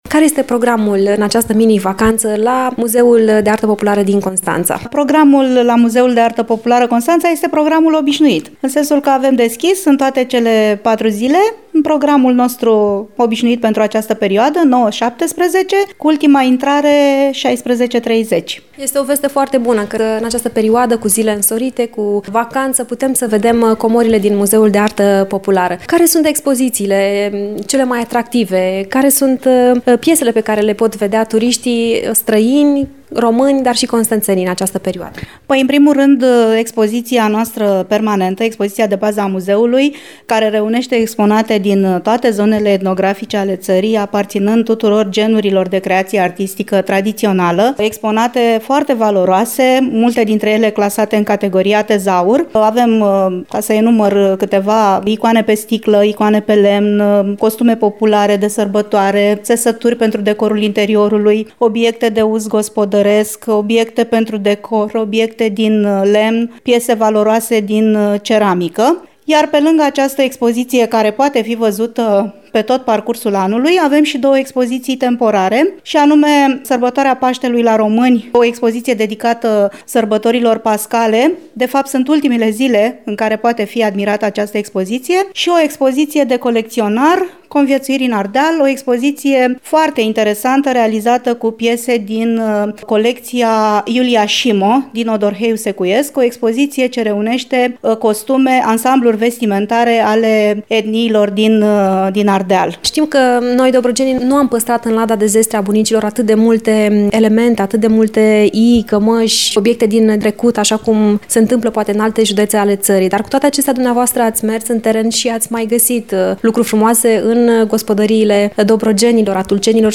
La Muzeul de Artă Populară, vizitatorii vor putea admira expozițiile permanente, dar și expoziția temporară dedicată Paștelui, aici unde au fost expuse și obiecte foarte vechi, tradiționale din satele dobrogene, achiziționate cu ocazia cercetărilor din teren făcute de specialiștii instituției de cultură, în lunile trecute. Cu detalii, muzeograful